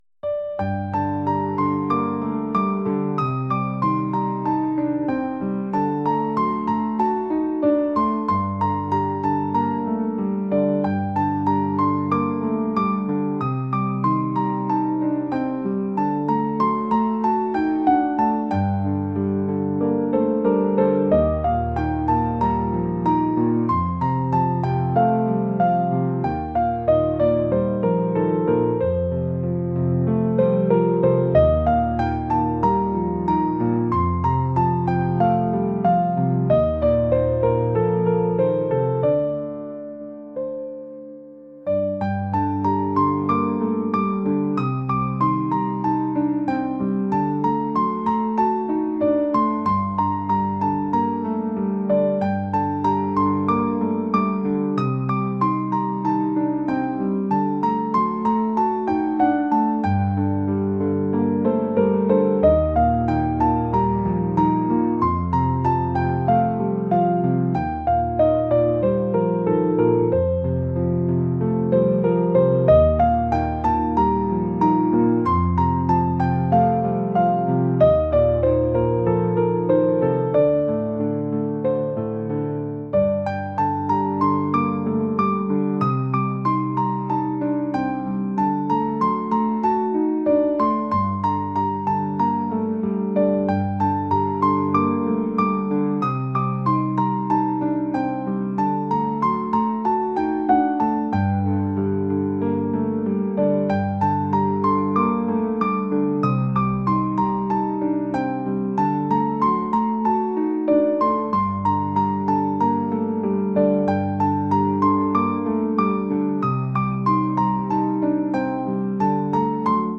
classical | ambient | cinematic